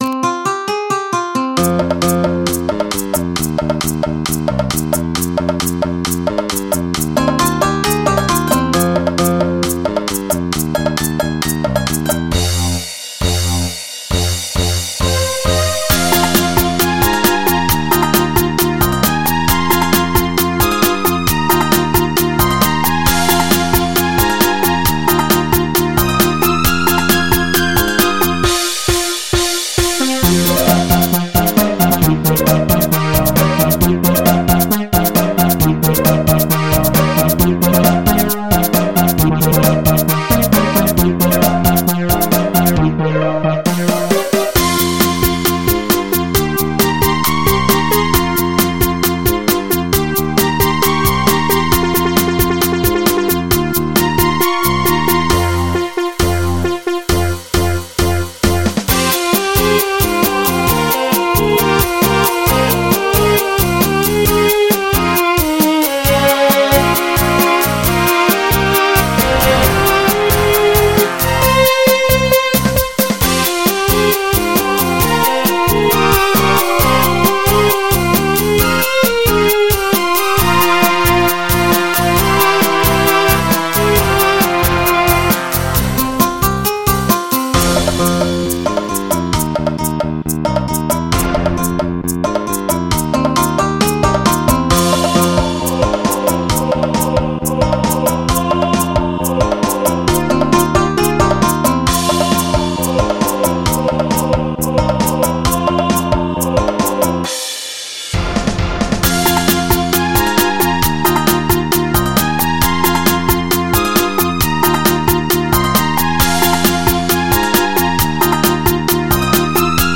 MIDI 28.96 KB MP3